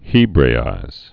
(hēbrā-īz)